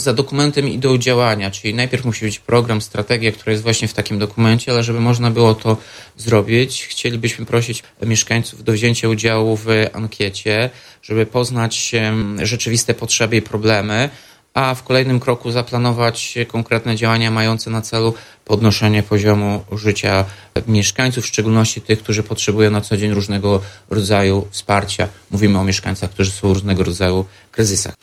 – W związku z tym zapraszamy wszystkich pełnoletnich mieszkańców Ełku do wypełnienia krótkiej ankiety – mówi Tomasz Andrukiewicz, prezydent miasta.